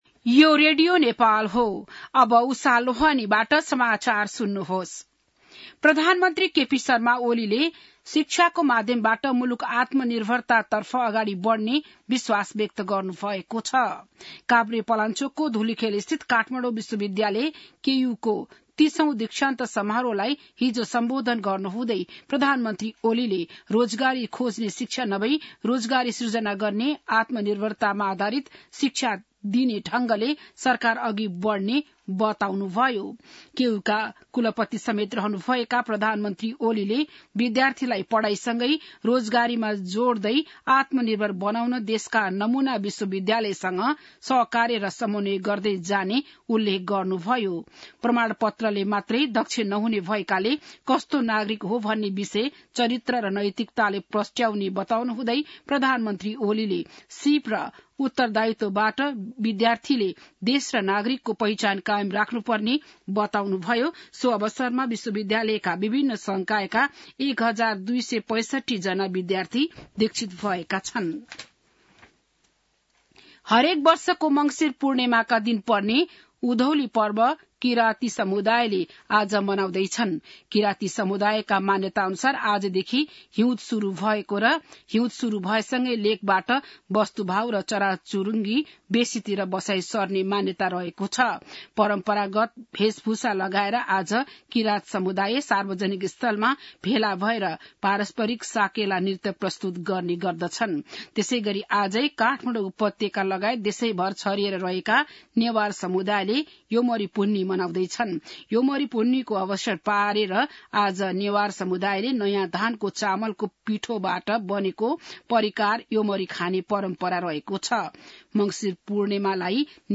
बिहान १० बजेको नेपाली समाचार : १ पुष , २०८१